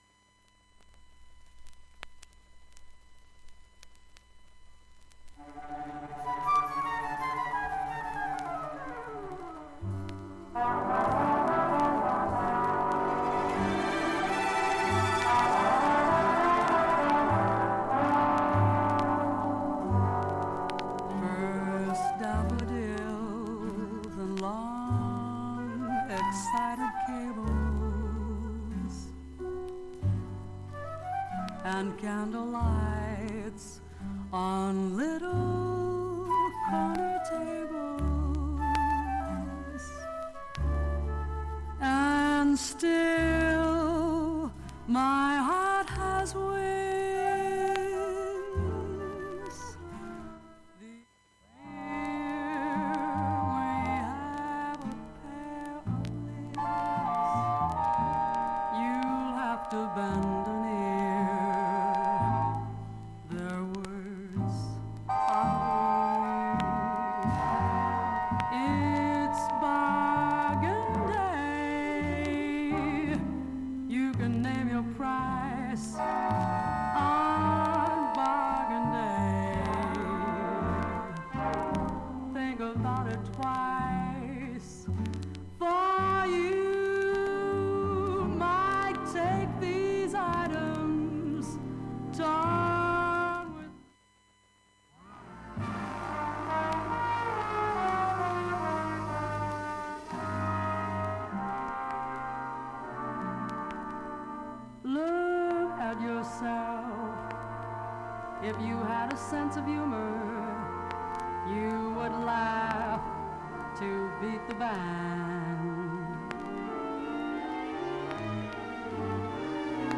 かすかな周回チリがたまに聴こえますが、
無音部もクリアで普通に音質は良好です。
軽い周回チリが出ています。ここが
一番チリ音が大きいと思います。
現物の試聴（上記録音時間4分）できます。音質目安にどうぞ
◆ＵＳＡ盤オリジナルSTEREO
ハスキーで味わい深い彼女のヴォーカルを
存分に堪能出来る極上のバラード集